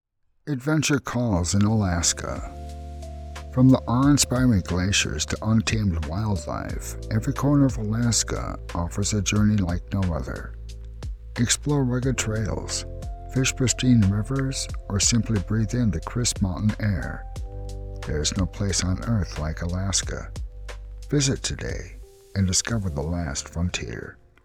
Meet a voice that captivates and connects—deep, gritty, and authentic.
Alaska Calls- Warm, Authentic, Charismatic, Resonant, Authentic, Genuine
North American Midwest, Upper Midwest
Middle Aged